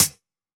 UHH_ElectroHatD_Hit-13.wav